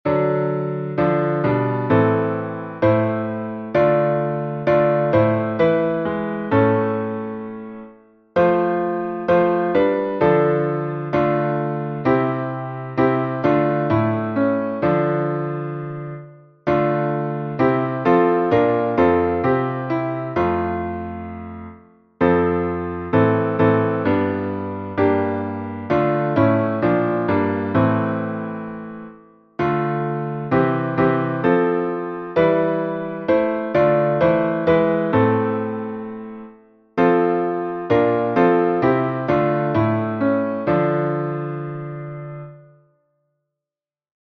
Modo: dórico
salmo_114A_instrumental.mp3